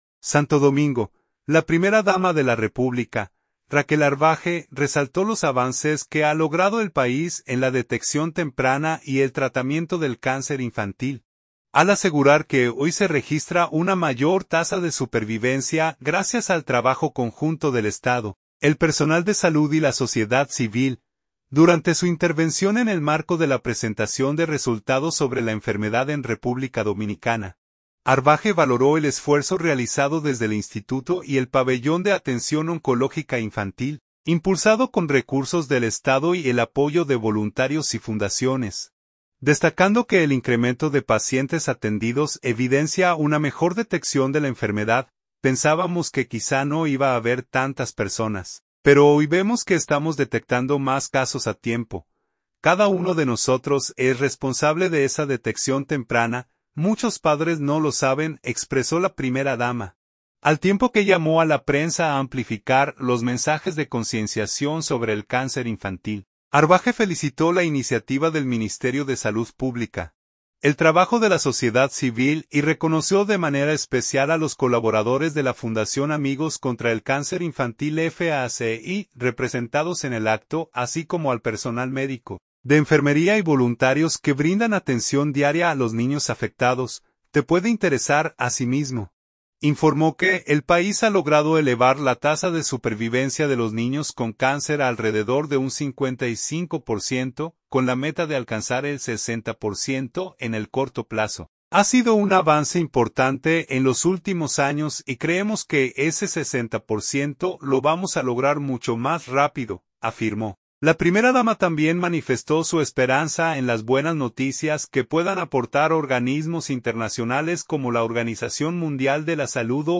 Durante su intervención en el marco de la presentación de resultados sobre la enfermedad en República Dominicana, Arbaje valoró el esfuerzo realizado desde el Instituto y el Pabellón de atención oncológica infantil, impulsado con recursos del Estado y el apoyo de voluntarios y fundaciones, destacando que el incremento de pacientes atendidos evidencia una mejor detección de la enfermedad.